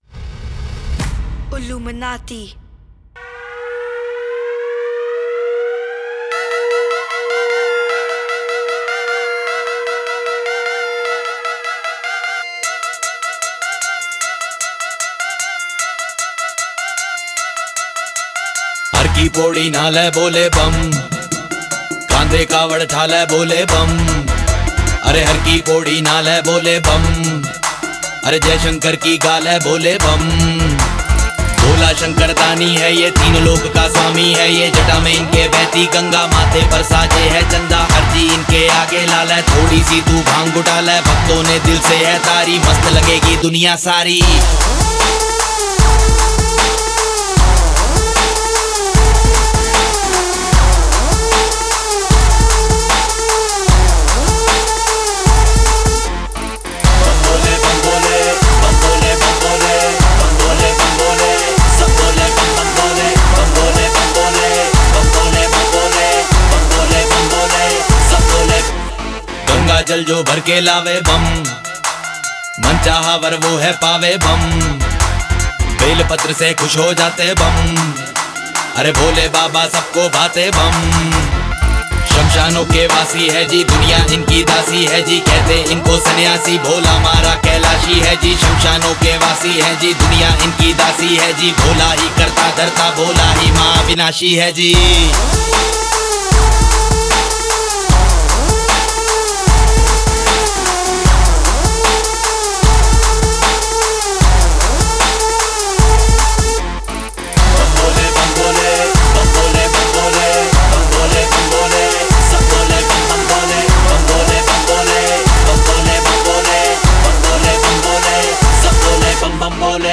Indian Pop